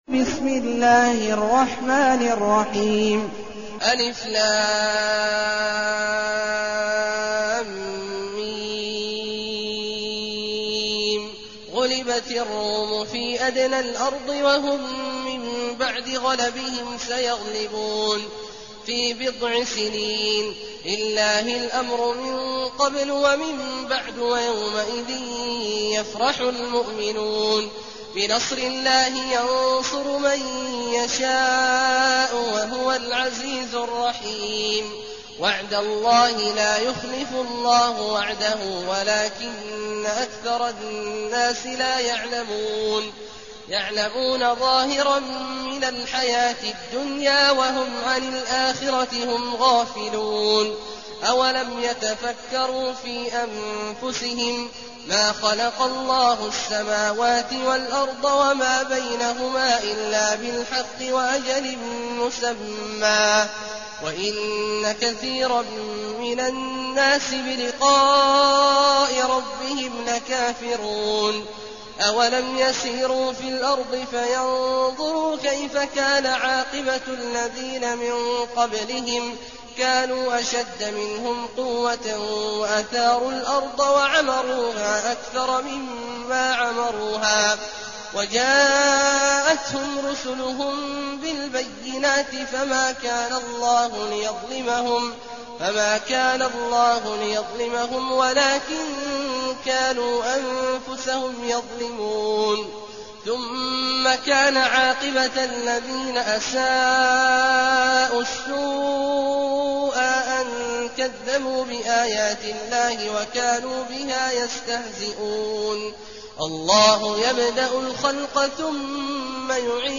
المكان: المسجد النبوي الشيخ: فضيلة الشيخ عبدالله الجهني فضيلة الشيخ عبدالله الجهني الروم